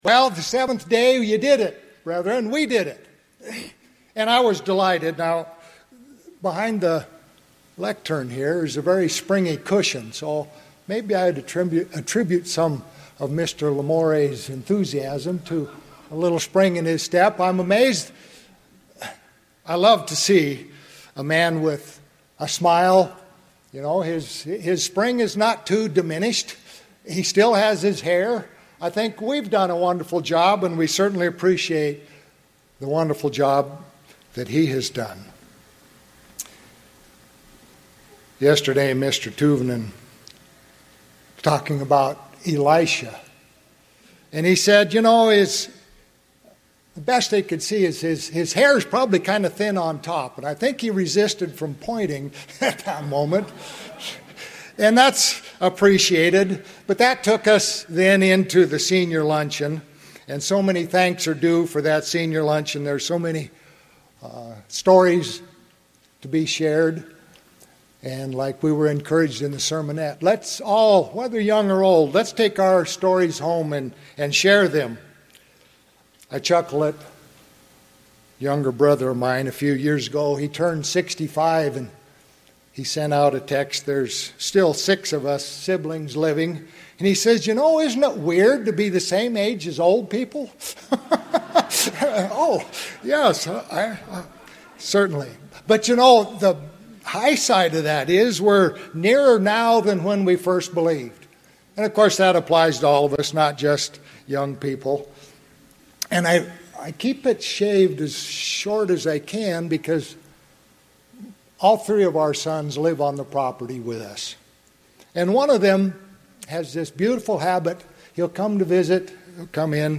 Sermons
Given in Rapid City, South Dakota